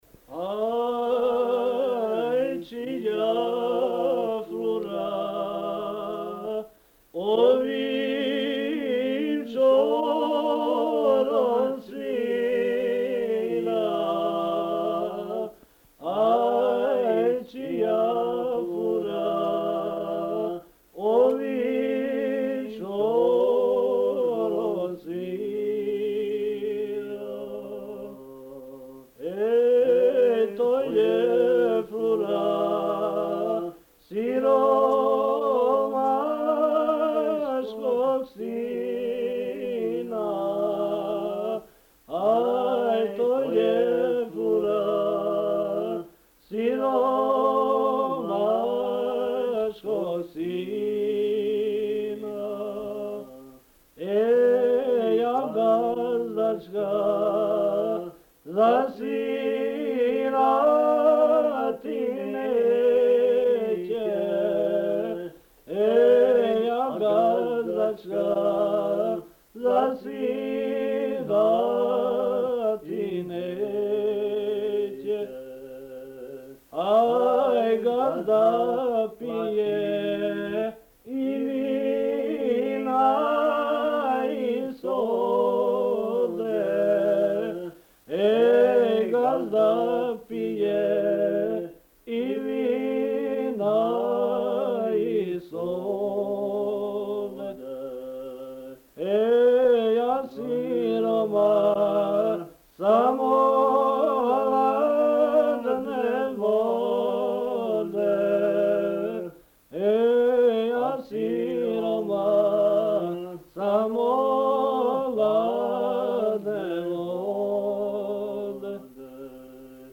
Tema: Pripevi, bećarci, kratke, potrkušice, šalajke, šaljive, nabrajalice, pesme iz mehane, pesme za ples
Mesto: Batanja (snimljeno u Kalazu)
Napomena: Lagani bećarac. Reč je o široko rasprostranjenoj i poznatoj pesmi, ispevanoj u maniru pevanja na bas.